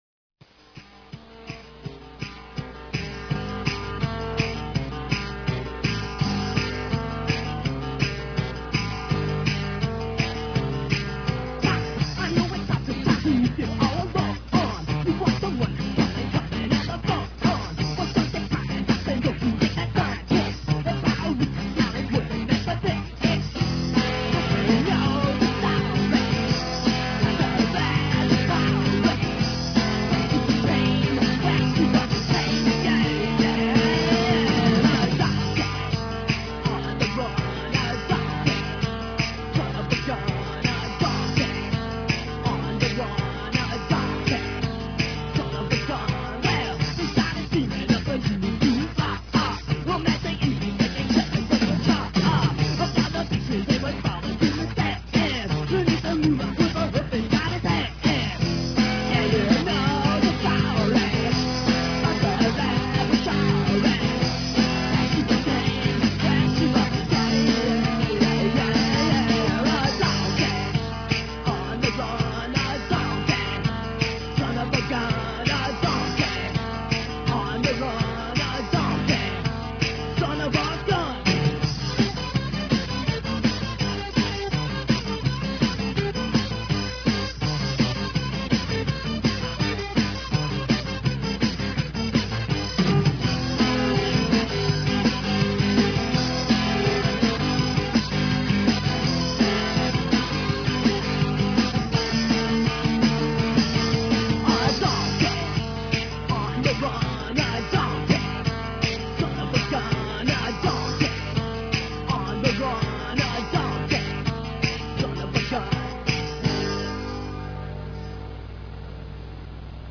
Acoustic Punk Rock meets Stand-up Comedy.